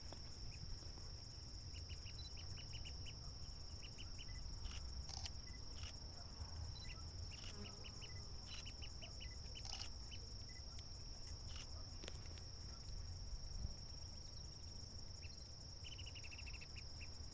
Tachurí Coludo (Culicivora caudacuta)
Nombre en inglés: Sharp-tailed Grass Tyrant
Localidad o área protegida: Reserva Natural Urutaú
Condición: Silvestre
Certeza: Fotografiada, Vocalización Grabada